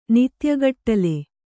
♪ nityagaṭṭale